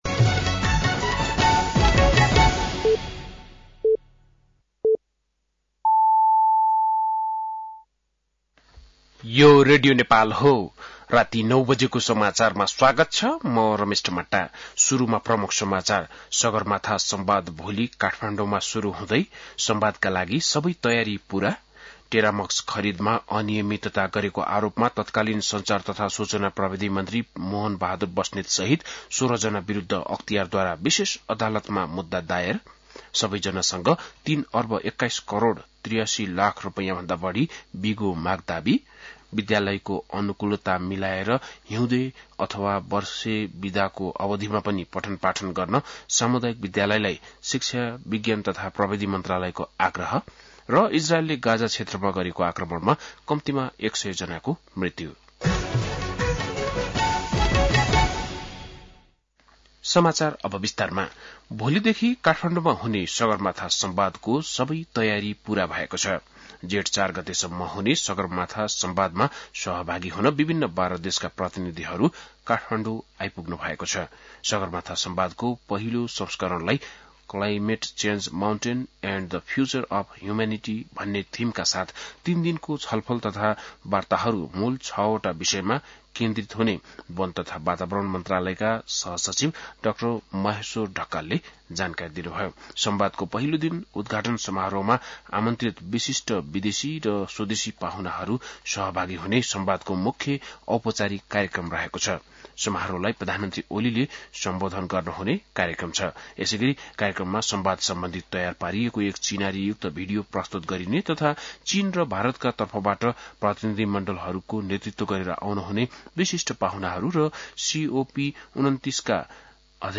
बेलुकी ९ बजेको नेपाली समाचार : १ जेठ , २०८२
9-pm-nepali-news-1.mp3